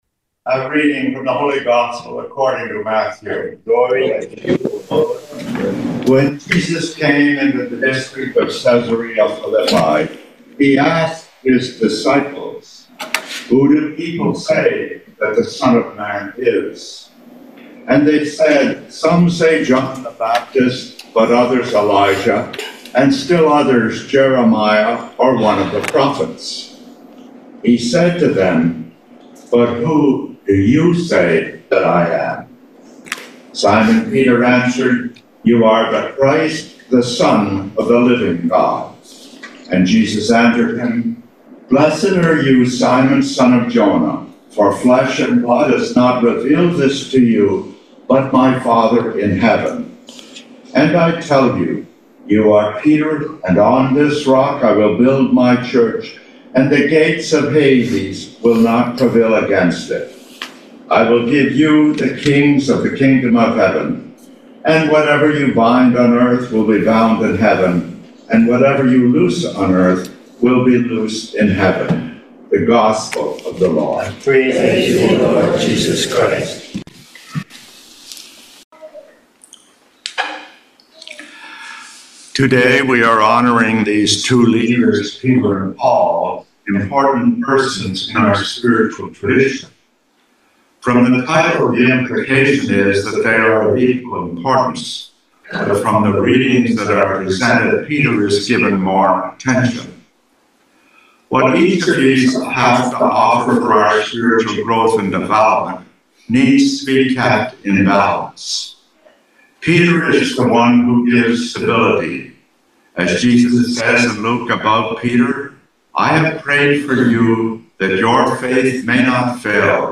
St. Mark’s Parish Homily